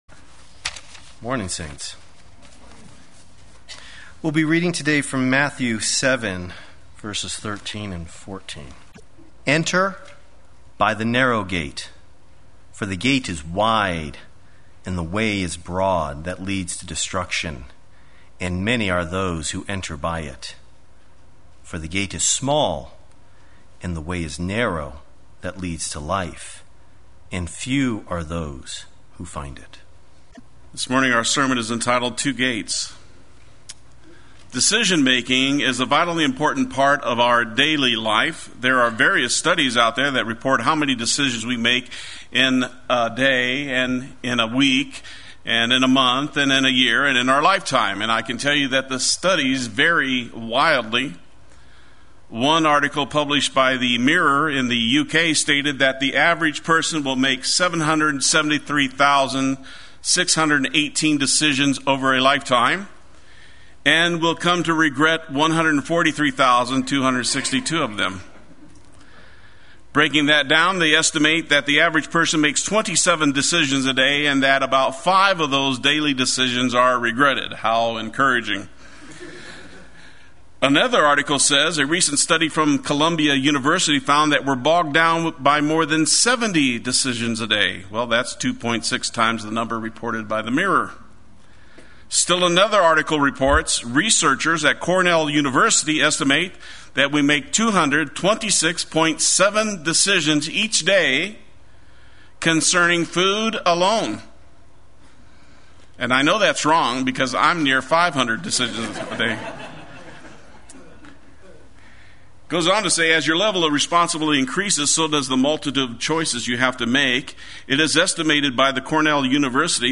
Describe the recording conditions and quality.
Two Gates Sunday Worship